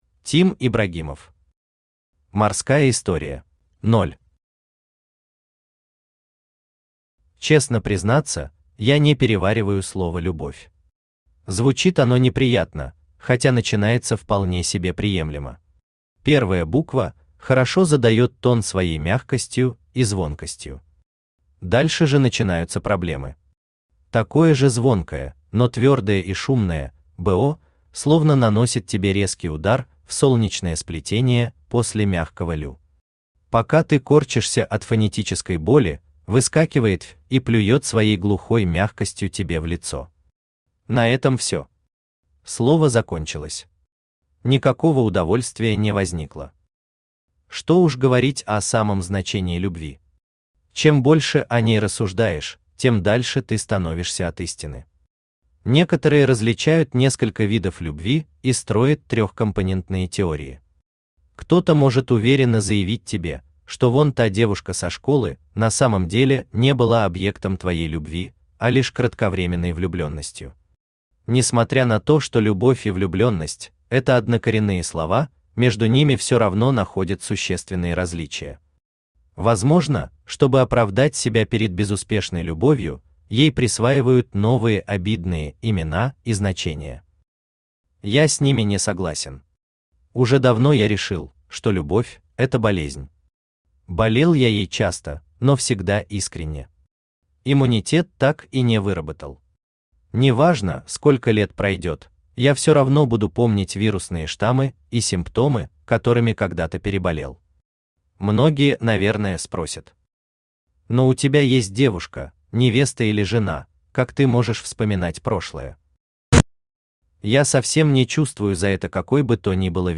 Aудиокнига Морская история Автор Тим Ибрагимов Читает аудиокнигу Авточтец ЛитРес. Прослушать и бесплатно скачать фрагмент аудиокниги